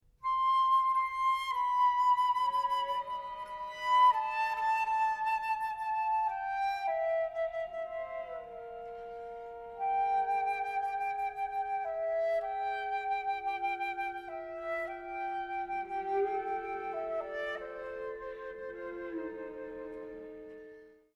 flute
cello
piano